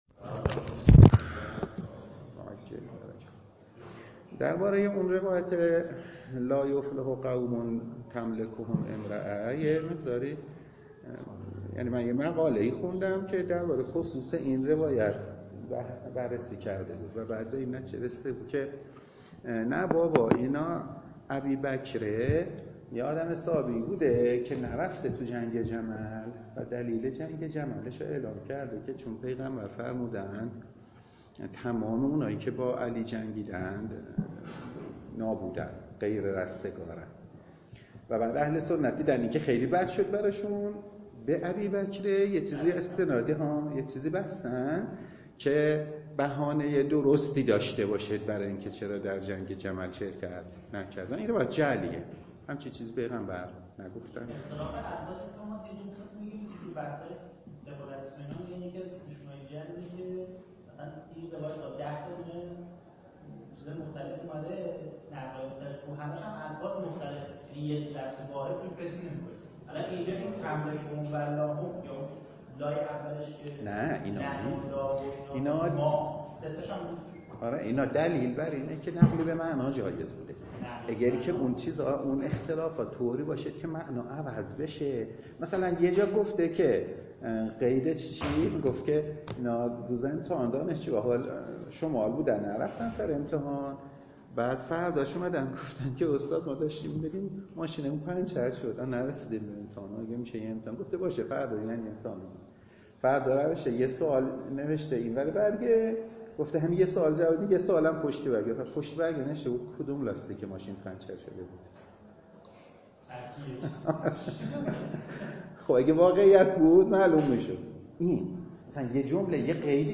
درس خارج فقه ولایت فقیه